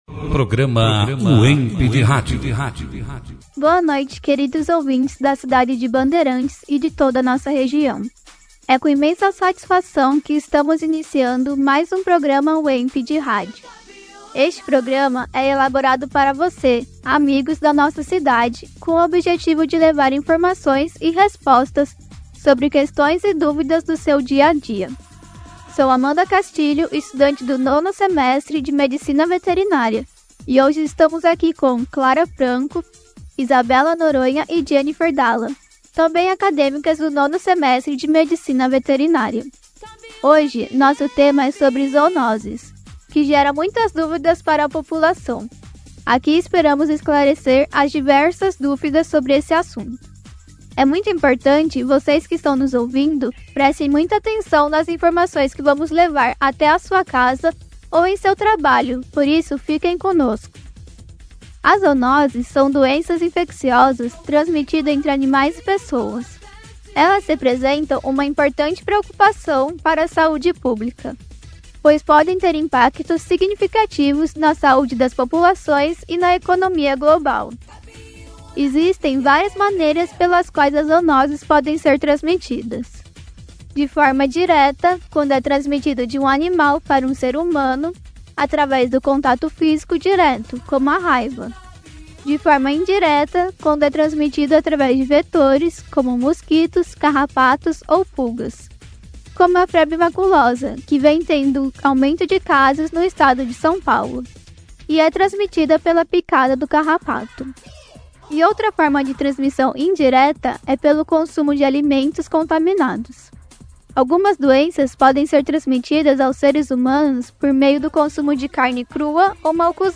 Produzido e apresentado pelos alunos, Acadêmicos do 5º ano do curso Medicina Veterinária